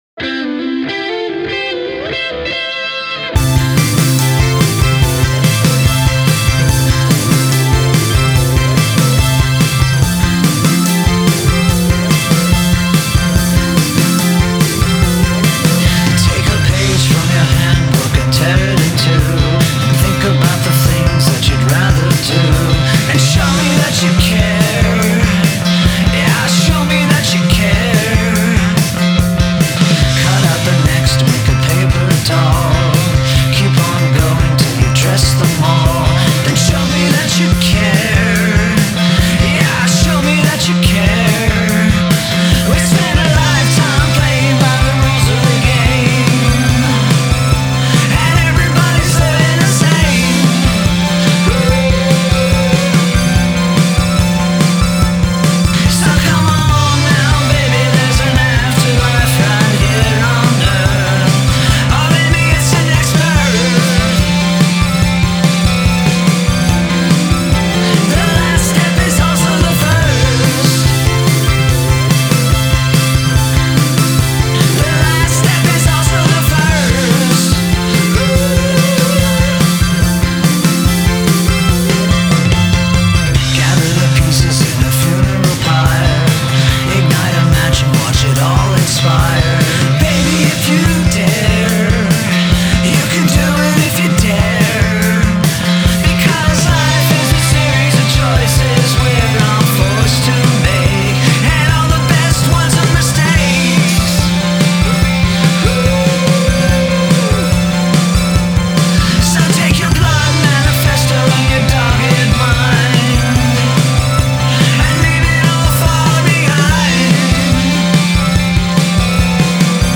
killer opening, great production, cool synths.